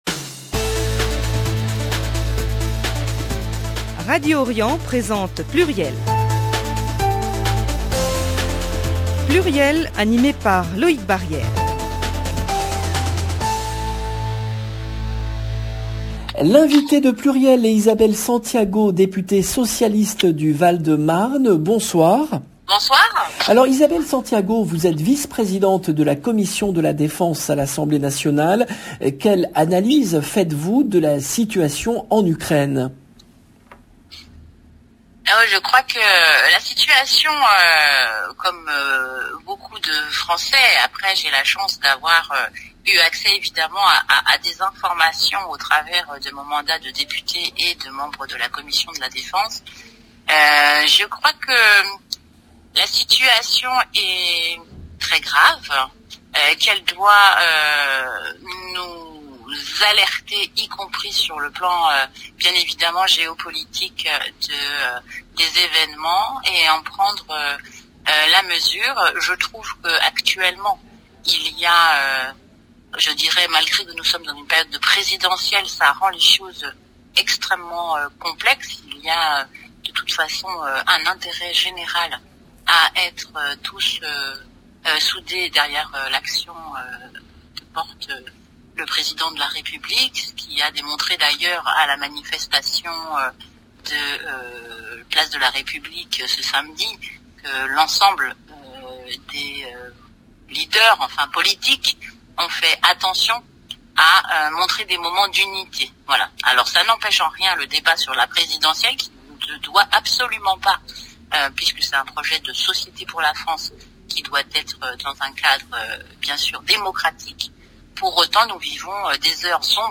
Isabelle Santiago, députée socialiste du Val-de-Marne.
le rendez-vous politique du mardi 8 mars 2022 L’invitée de PLURIEL est Isabelle Santiago , députée socialiste du Val-de-Marne.